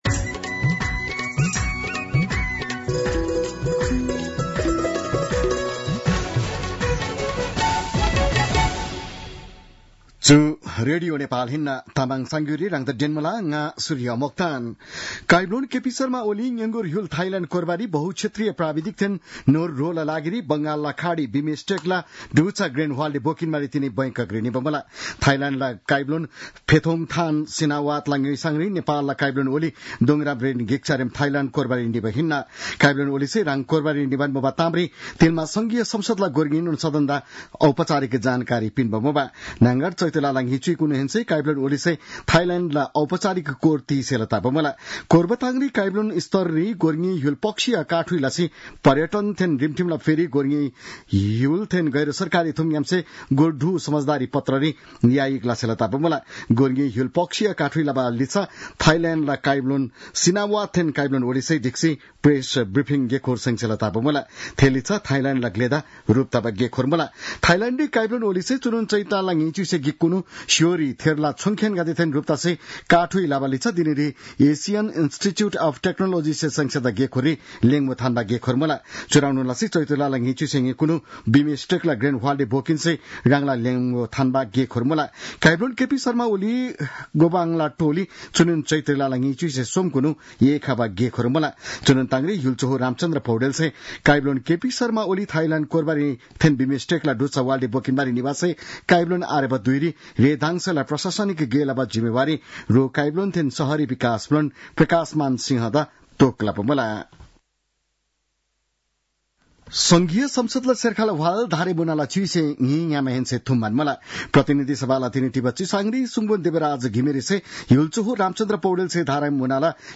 तामाङ भाषाको समाचार : १९ चैत , २०८१